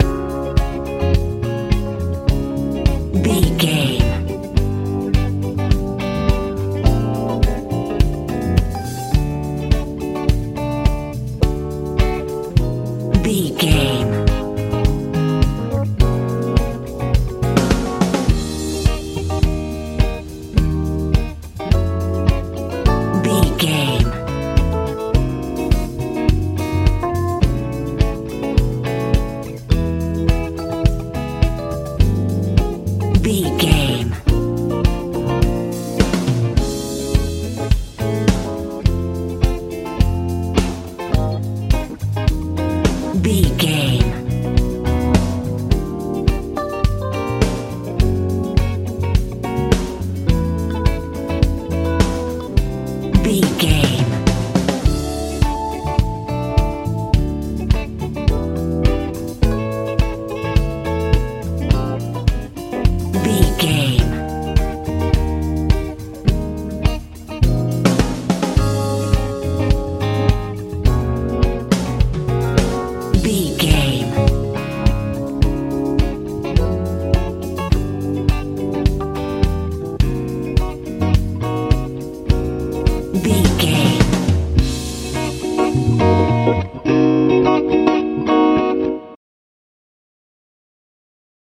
rnb soul feel
Ionian/Major
C♯
groovy
funky
organ
electric guitar
bass guitar
drums